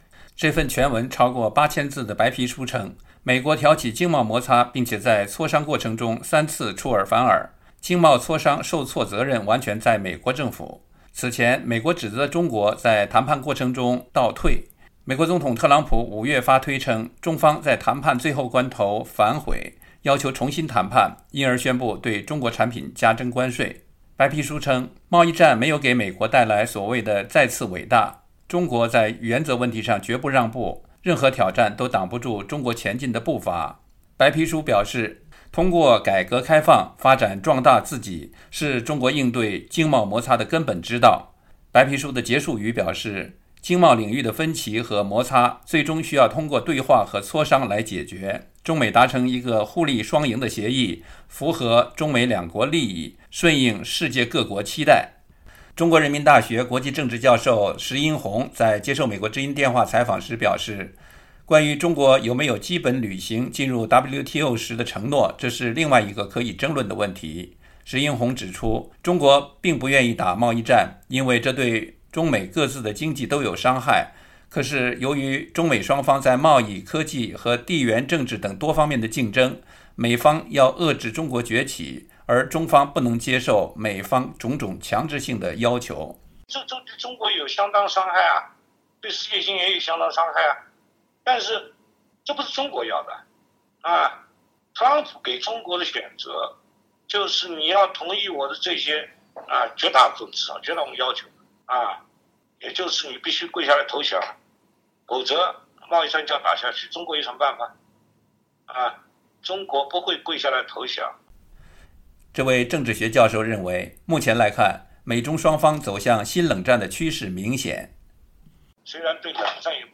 对于这份白皮书没有提到中国被指仍未兑现加入WTO时所作的一些重要承诺，中国人民大学国际政治教授时殷弘在接受美国之音电话采访时表示，关于中国有没有基本履行进入WTO时的承诺，这是另外一个可以争论的问题。